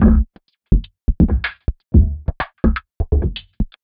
tx_perc_125_grundle.wav